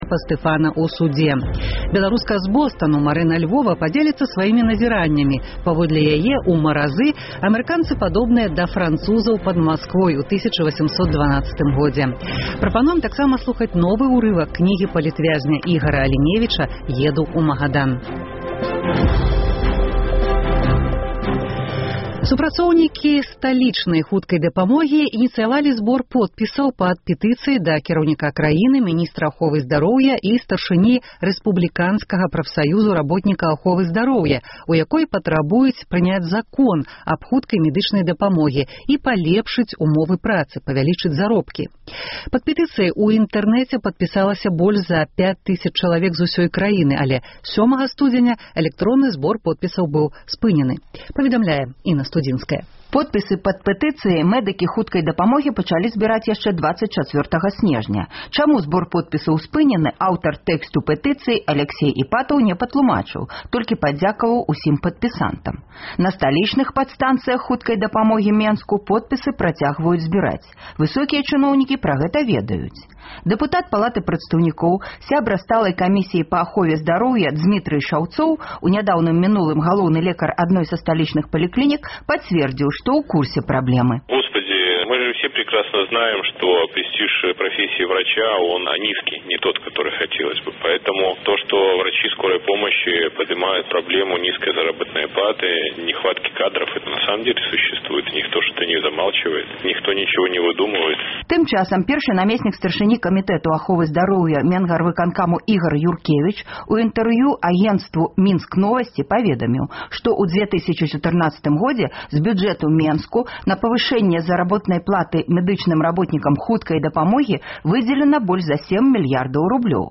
Чаму тады мэдыкі хуткай дапамогі спынілі збор подпісаў пад зваротам да ўладаў? Галасы прафесіі, рэпартаж з Магілёўшчыны.